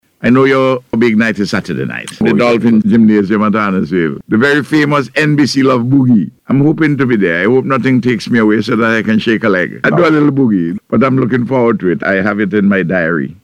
He gave the encouragement during the Face to Face programme aired on NBC Radio this morning.